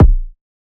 KICK 11.wav